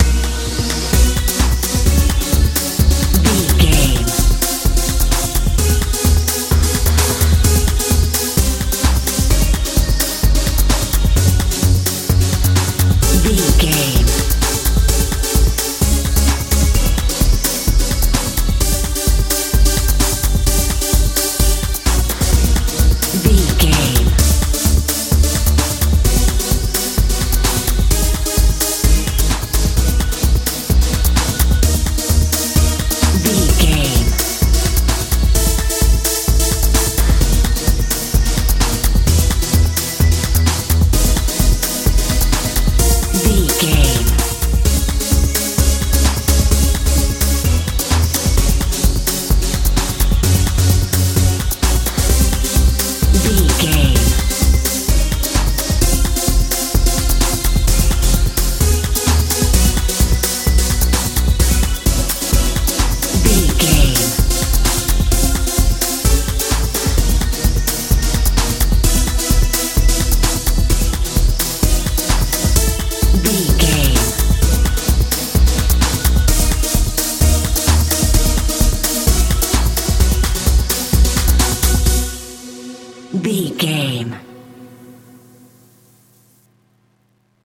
modern dance feel
Ionian/Major
magical
mystical
synthesiser
bass guitar
drums
80s
90s
tension
suspense
powerful